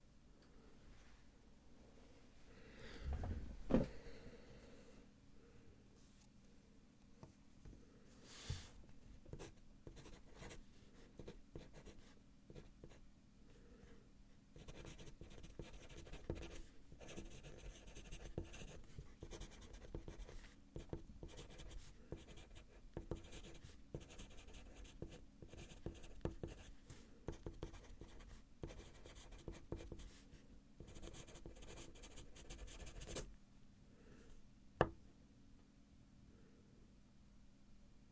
钢笔书写
描述：录制我用钢笔在纸上写东西。
Tag: 签名 标志 铅笔